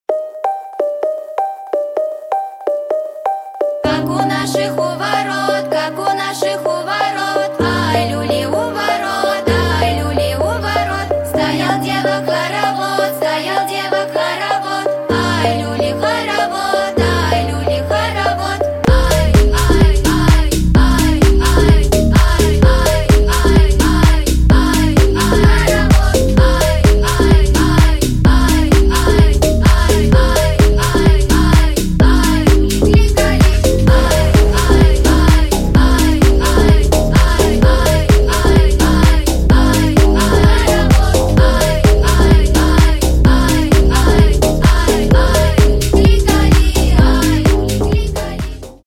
2025 » Новинки » Русские » Поп Скачать припев